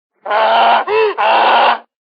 PixelPerfectionCE/assets/minecraft/sounds/mob/horse/donkey/angry2.ogg at mc116